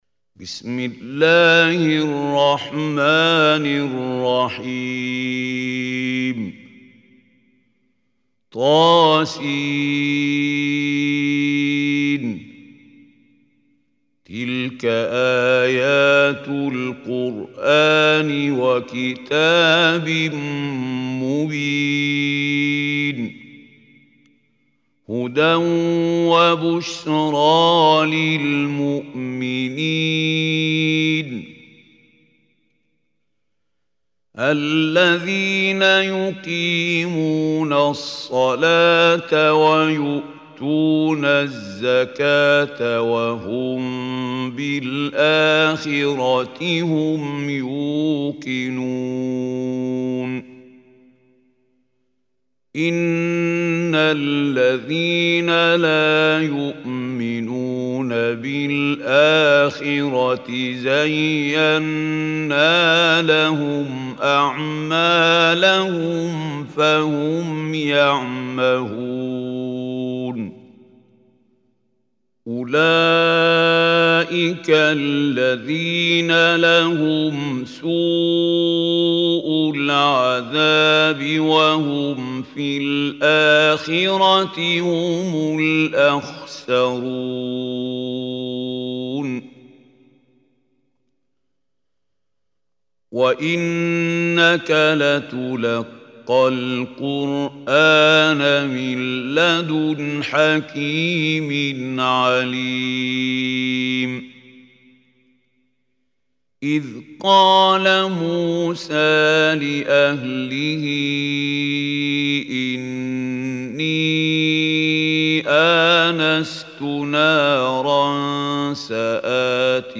Surah An Naml Online Recitation by Khalil Hussary
Surah an Naml is 27th chapter of Holy Quran. Download online recitation tilawat of Surah Naml in the beautiful voice of Mahmoud Khalil al Hussary.